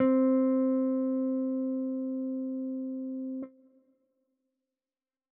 Wolf Guitar.wav